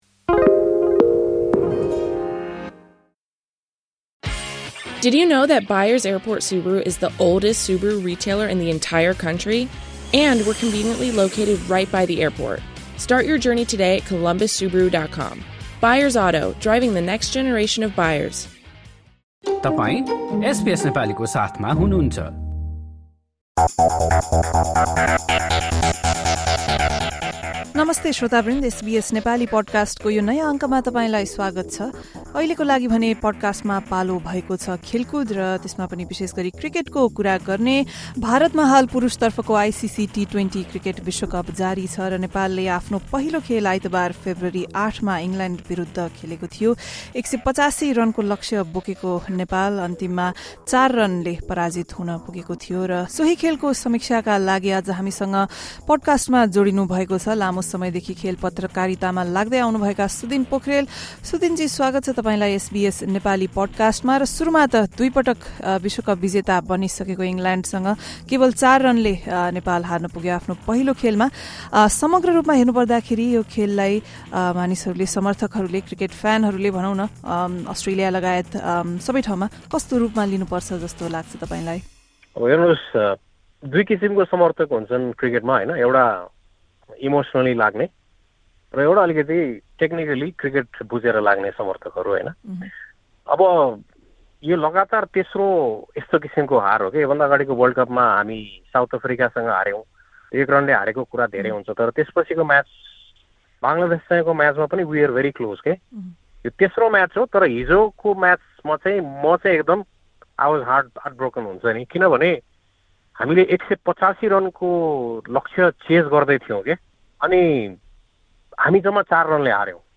एसबीएस नेपालीले गरेको कुराकानी